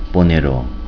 Ponero